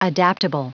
Prononciation du mot adaptable en anglais (fichier audio)
Prononciation du mot : adaptable